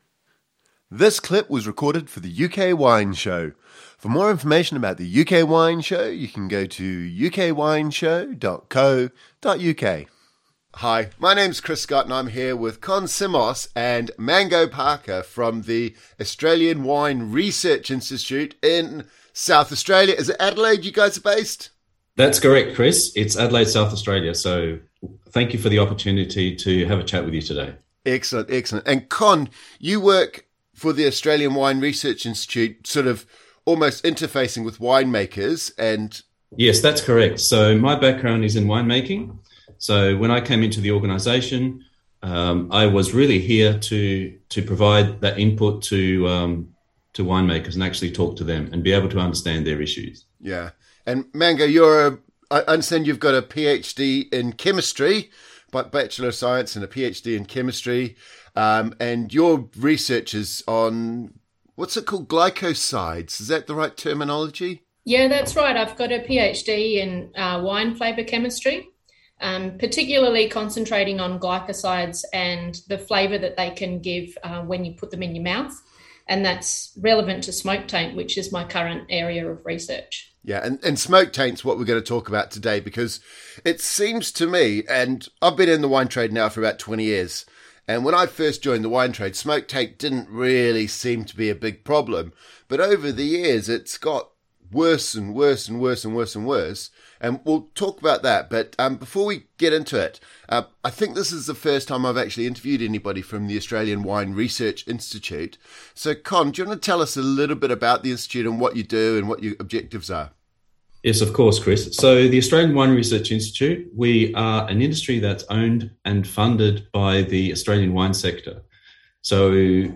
Overview This is the first interview on smoke taint